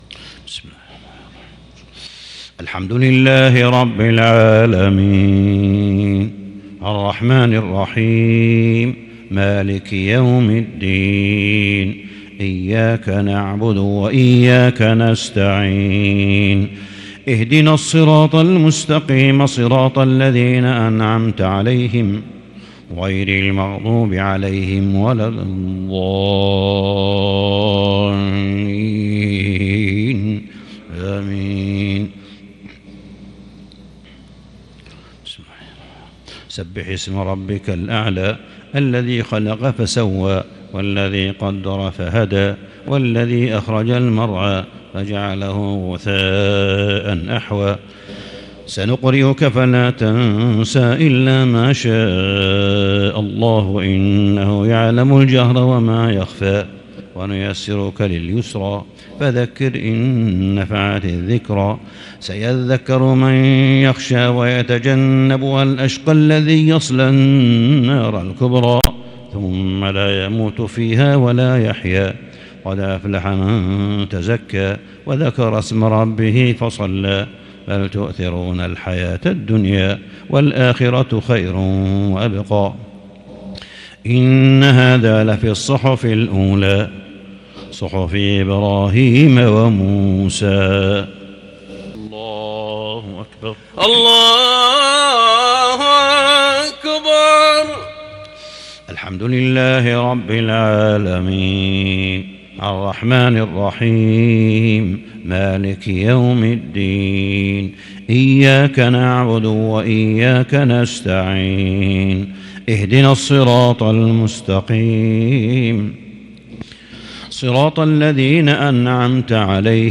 سورتي الأعلى والغاشية من صلاة الجمعة 23 محرم 1442هـ | 2020-09-11 Jumu'ah prayer Surah Al-A'laa and Al-Ghaashiya > 1442 🕋 > الفروض - تلاوات الحرمين